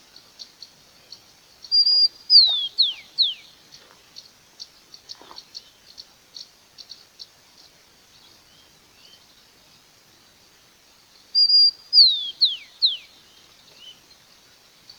Chingolo (Zonotrichia capensis)
2019_03_30-13_09_37-Rufous-collared-sparrow.mp3
Nombre en inglés: Rufous-collared Sparrow
Fase de la vida: Adulto
Localidad o área protegida: Bosque de Zárate
Condición: Silvestre
Certeza: Observada, Vocalización Grabada